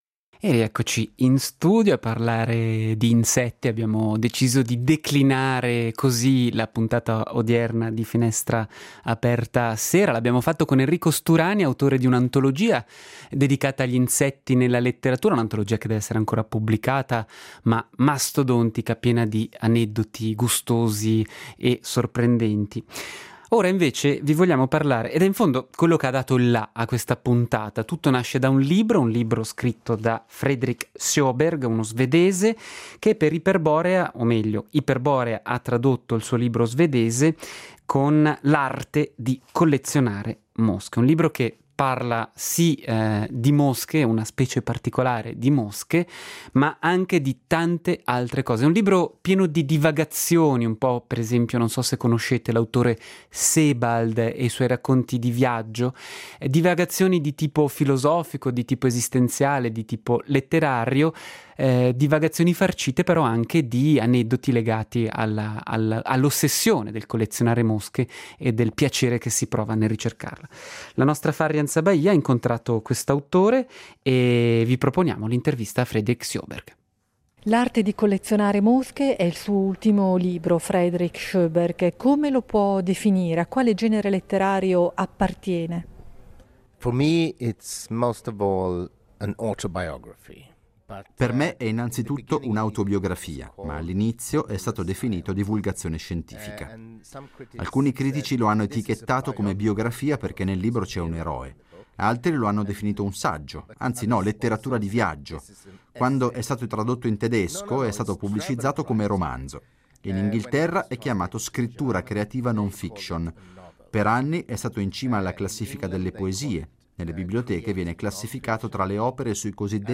Tra fiction, essai e autobiografia, non manca di far discutere e vendere bene il libro di "L'arte di collezionare mosche" di Fredrik Sjoberg. Intervista all'autore